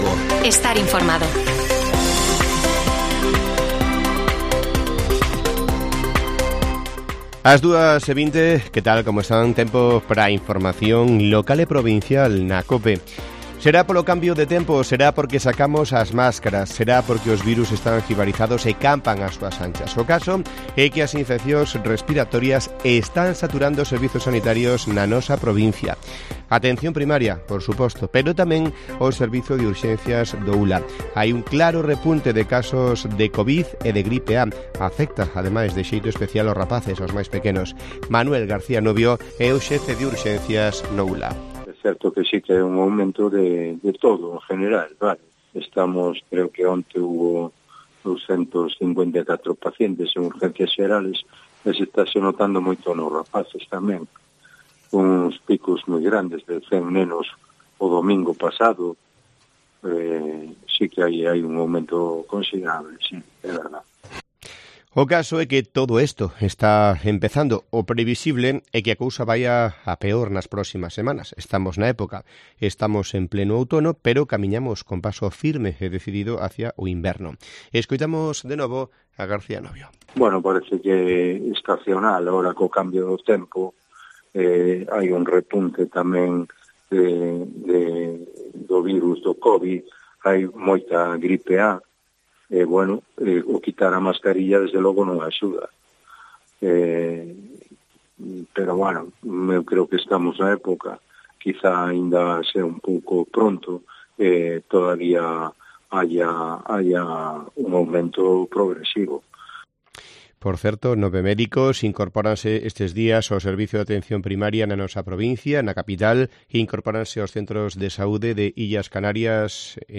Informativo Mediodía de Cope Lugo. 26 de octubre. 14:20 horas